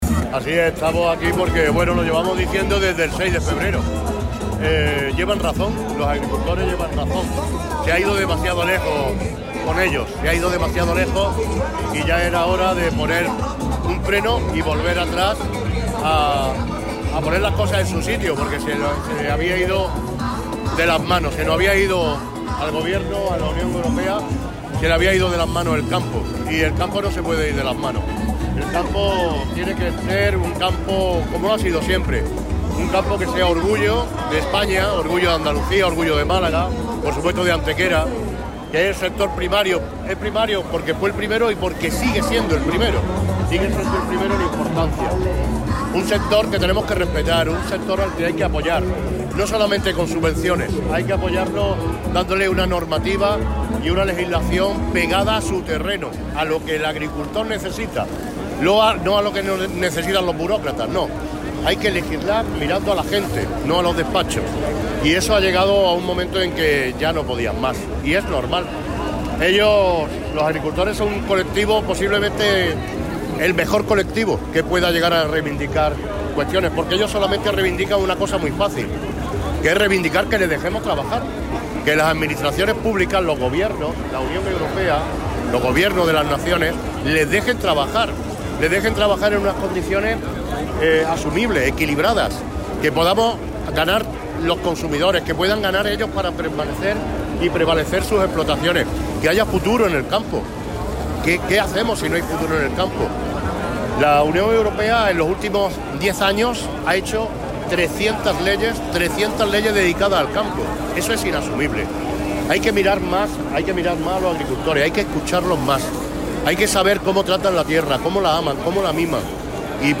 El PP de Málaga se ha posicionado hoy junto a los agricultores y ganaderos movilizados en la capital, donde alcaldes populares de toda la provincia les han mostrado su apoyo, encabezados por el regidor de Antequera, Manuel Barón, y el vicesecretario popular y primer edil de Riogordo, Antonio Alés.